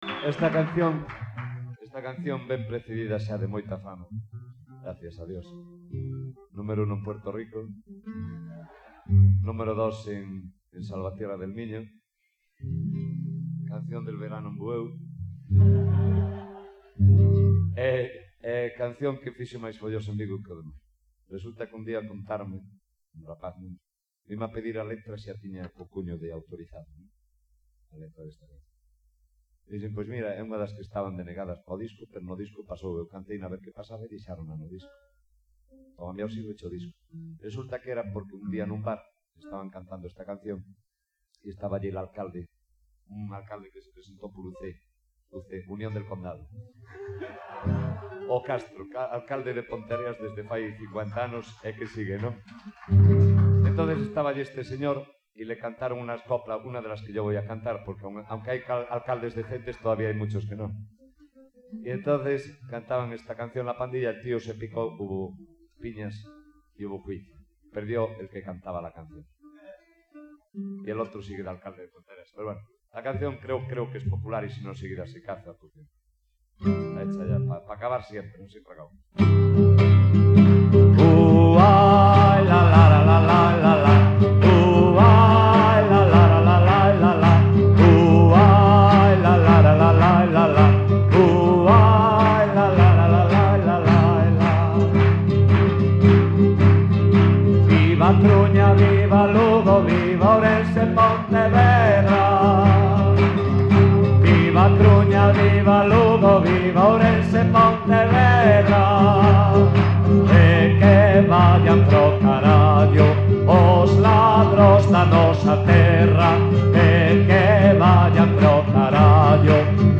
Letra: Popular